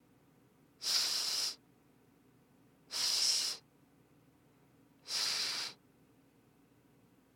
歯擦音を出す
音量注意！
歯擦音というのは超シンプルに説明するとサ行を発音するときに音の出だしに舌と歯の間を息が通過し鳴る音のことです。
いわゆる静かにしてって言うときの「シーッ🤫」ですね。